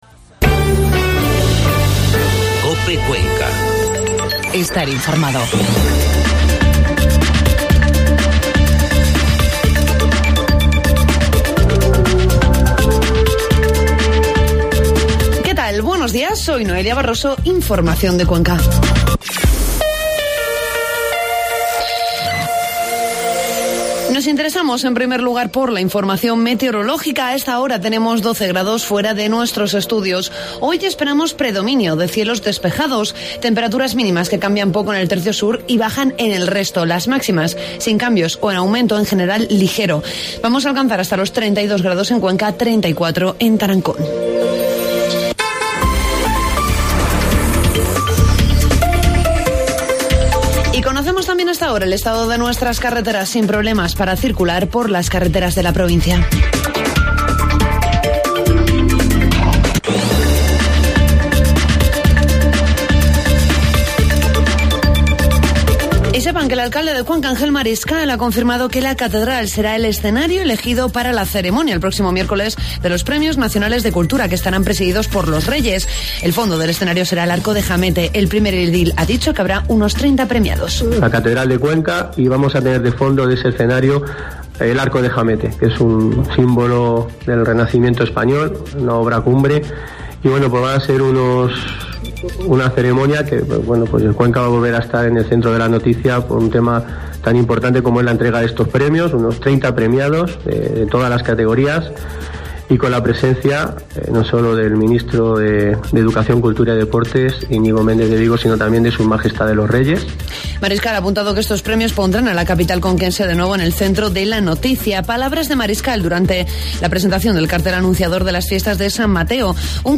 Informativo matinal COPE Cuenca 8 de septiembre